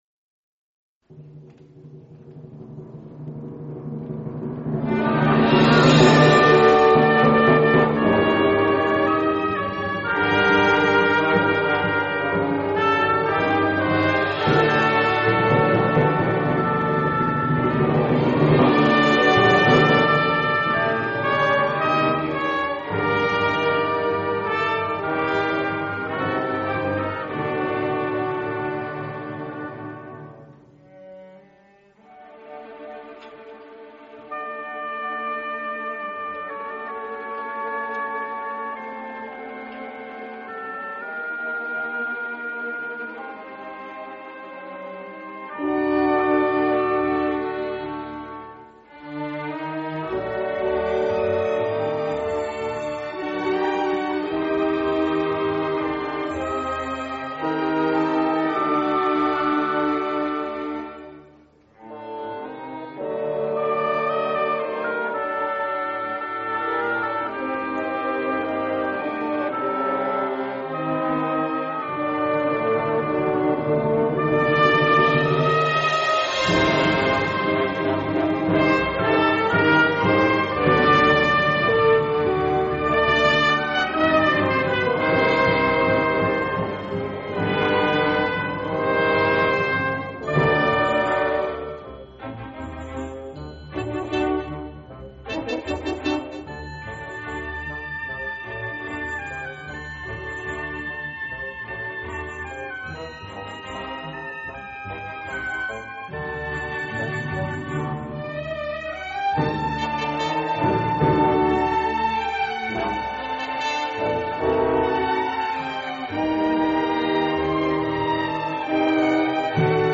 سرود اركسترال با مضمون دفاع مقدس
شیوه اجرا: اركستر